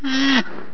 c_rhino_slct.wav